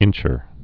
(ĭnchər)